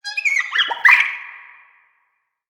Sfx_creature_seamonkey_seeshiny_01.ogg